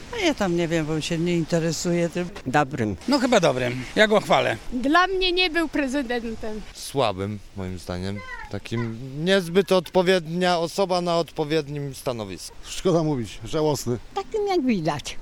Zapytaliśmy mieszkańców Stargardu także o to jak wspominają prezydenturę Andrzeja Dudy.